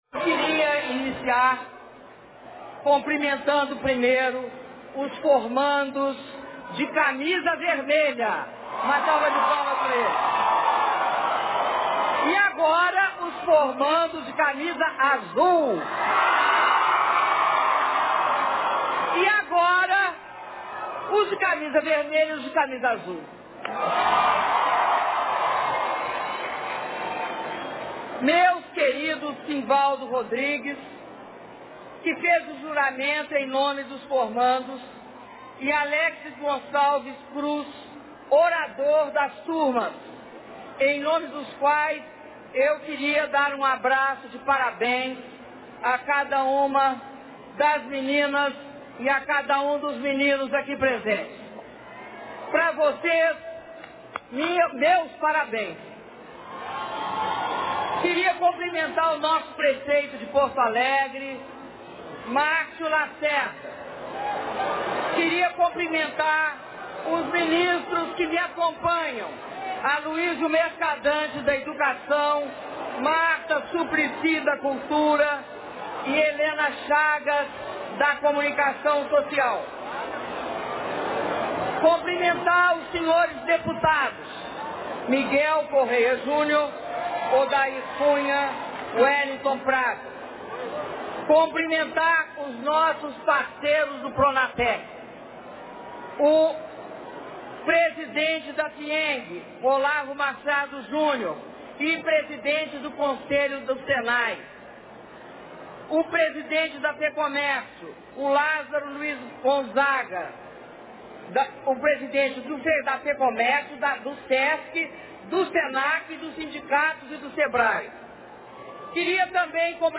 Discurso da Presidenta da República, Dilma Rousseff, durante a cerimônia de formatura de alunos do Pronatec - Belo Horizonte/MG